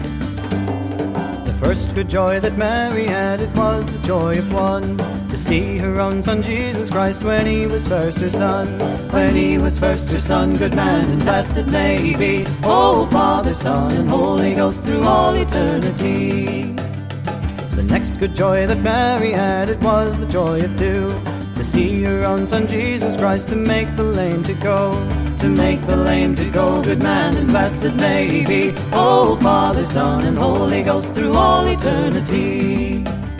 Flute, Vocals
Fiddle, Guitar, Vocals